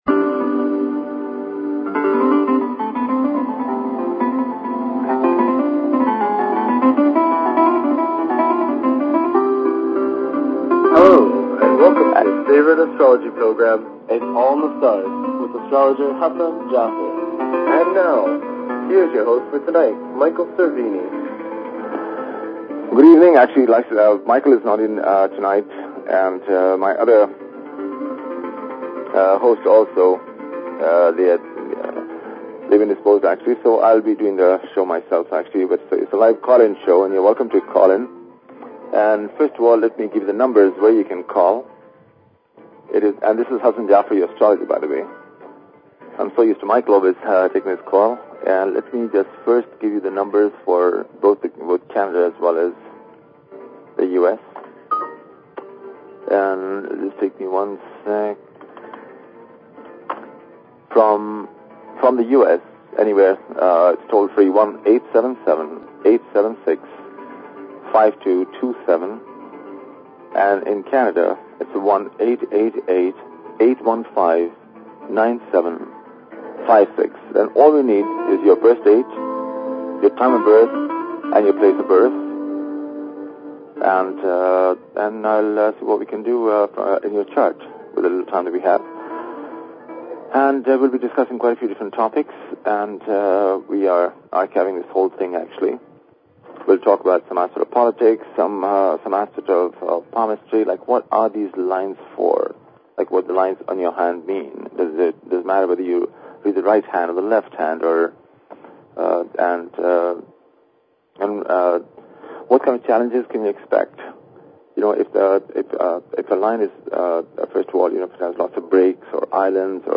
Talk Show Episode, Audio Podcast, Its_all_in_the_Stars and Courtesy of BBS Radio on , show guests , about , categorized as